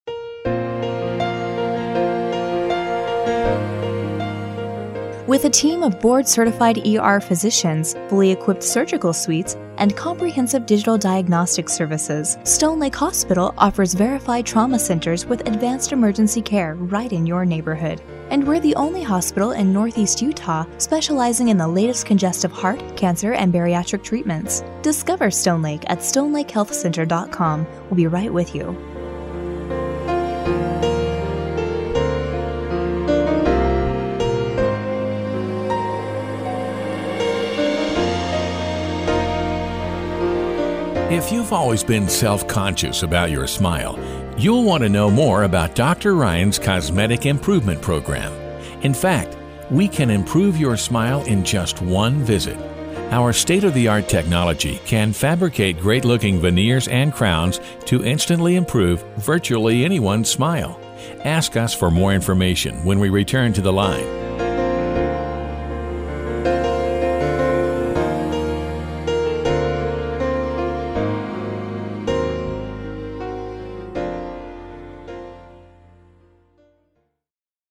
Messages on hold
Music on hold